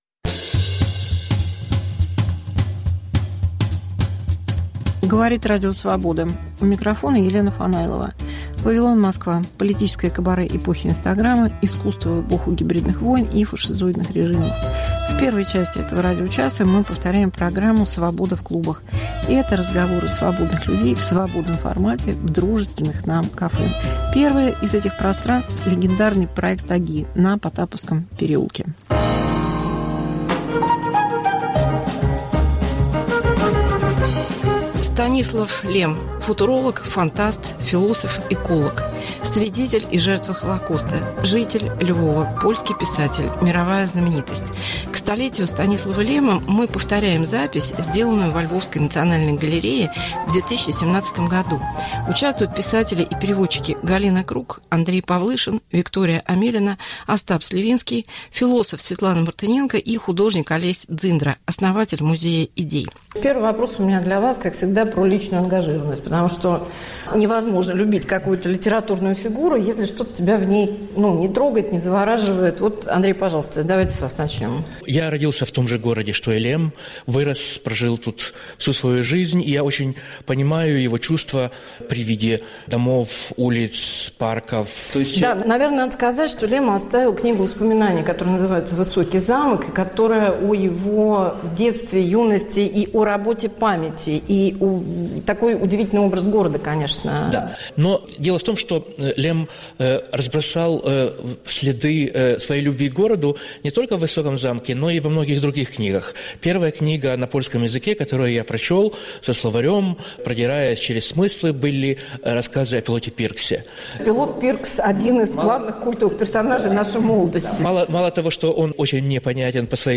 Мегаполис Москва как Радио Вавилон: современный звук, неожиданные сюжеты, разные голоса. 1.